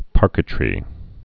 (pärkĭ-trē)